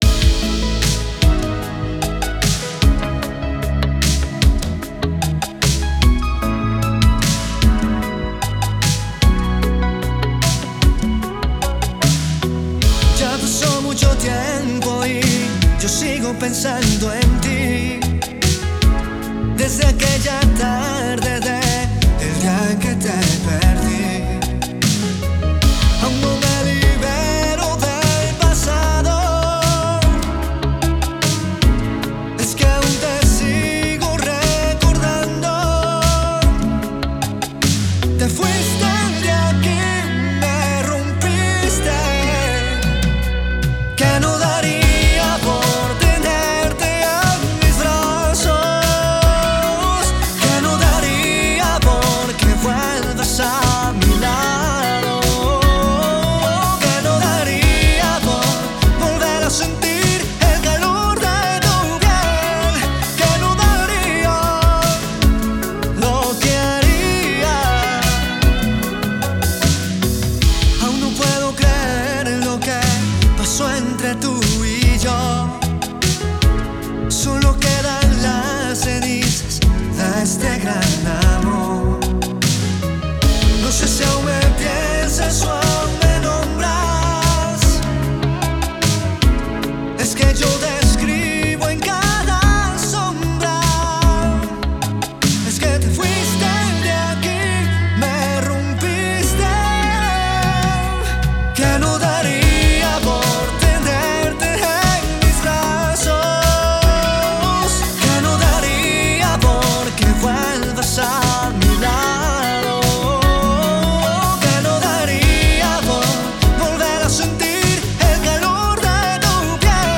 logrando un sonido íntimo pero a la vez poderoso.